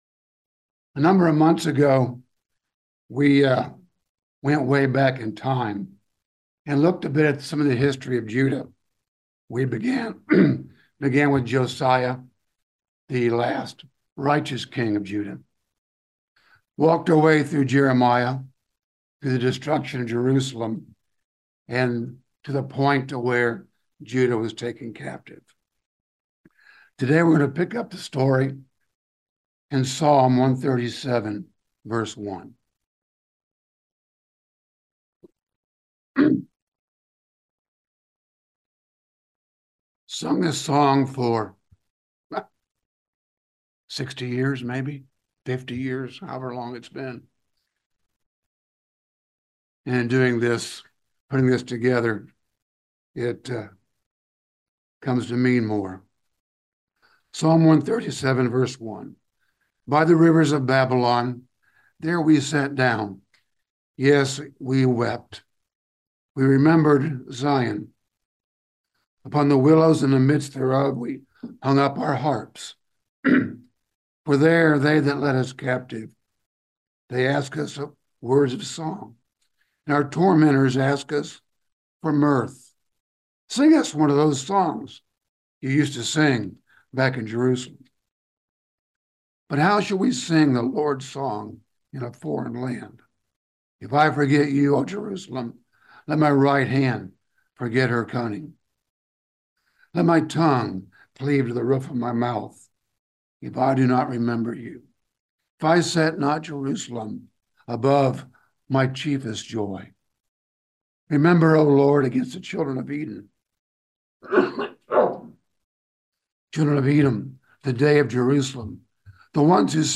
Given in London, KY